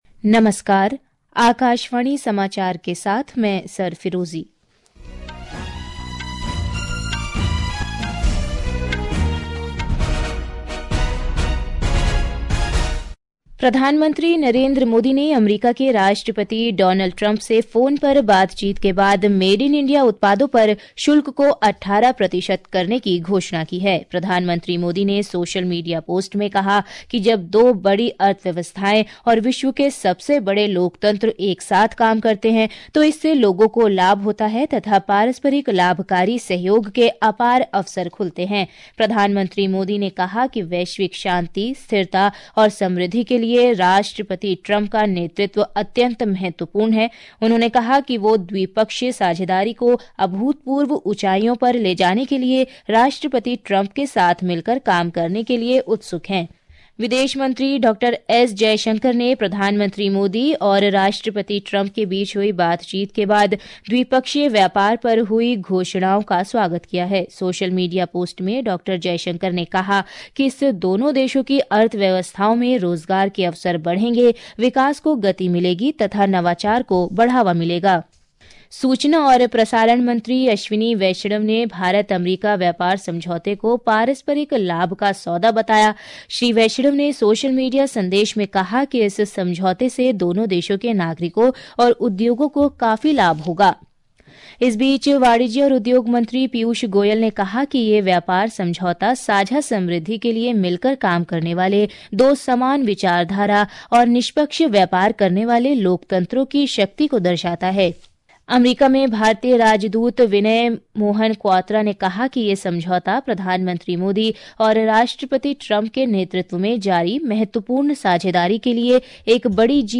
प्रति घंटा समाचार
प्रति घंटा समाचार | Hindi